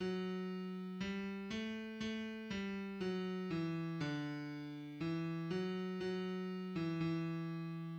\new Score { \new Staff { \relative c'' { \time 2/4 \key c \major \clef treble \tempo 4 = 88 \omit Score.MetronomeMark \partial 4 e8^\markup { \halign #-0.5 "Choral Fantasy"} e f( e d c) c( b a b) c( c d e) e( d) } } }